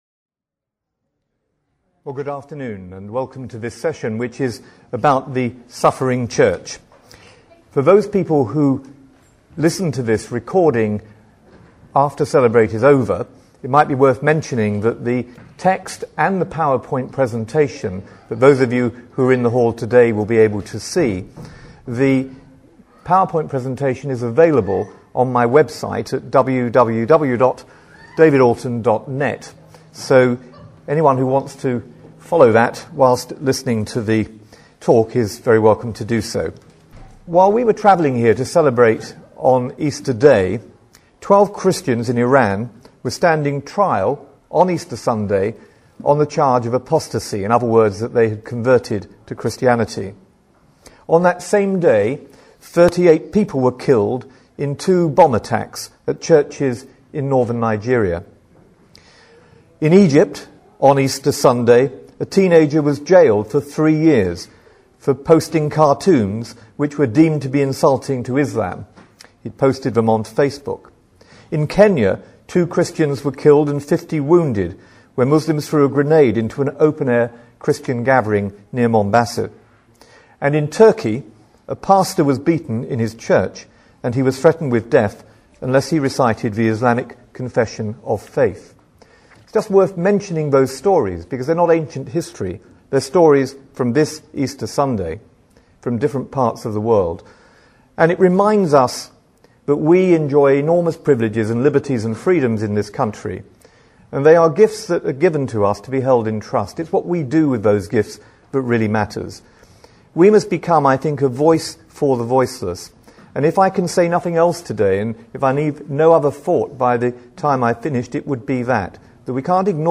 Talks and presentations